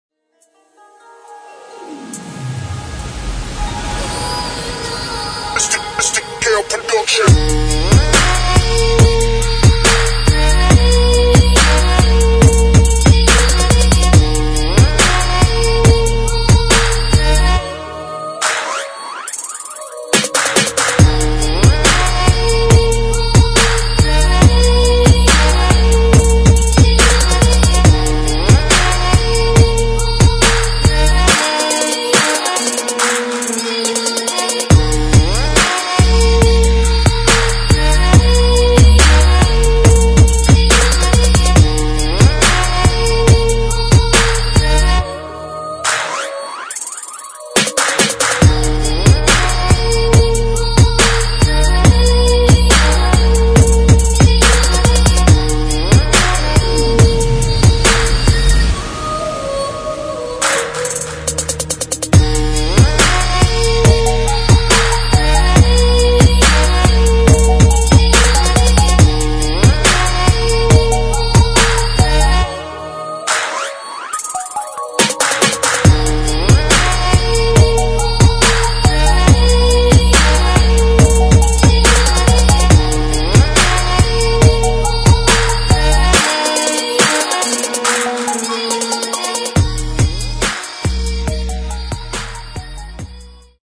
[ GRIME / DUBSTEP ]